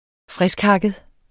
Udtale [ -ˌhɑgəð ]